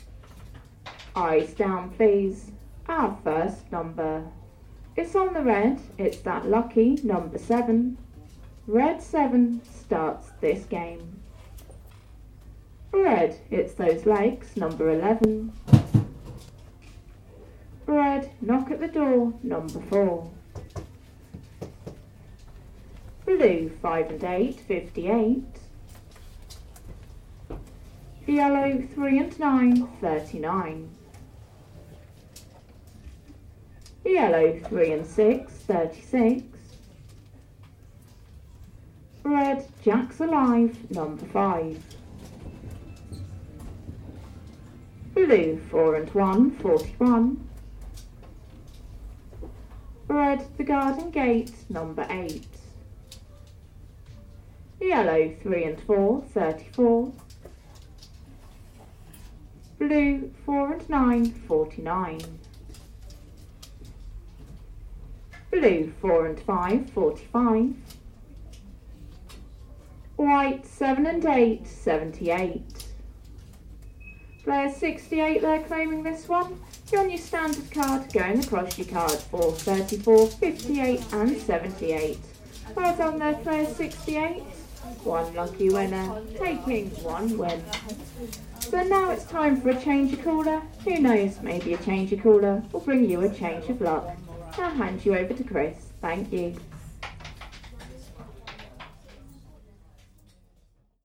Bingo-caller.mp3